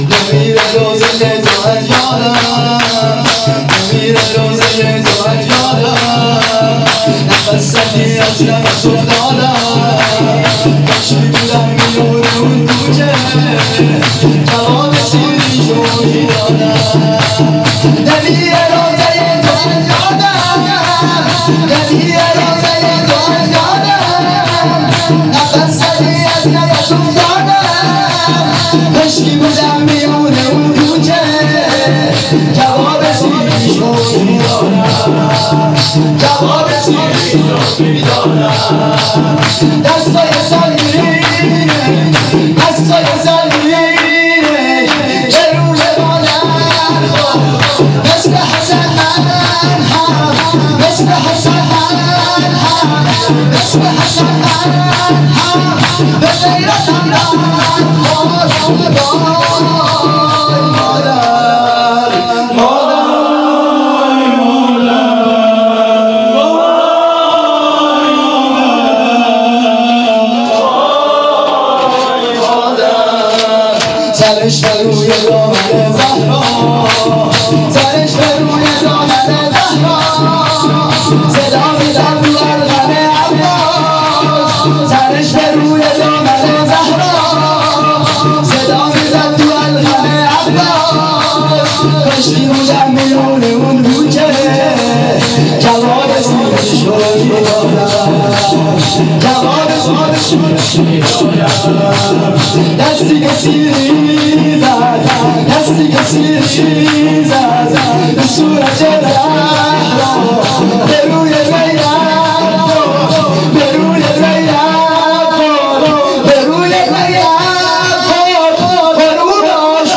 قائم آل محمد راوه - شور لطمه زنی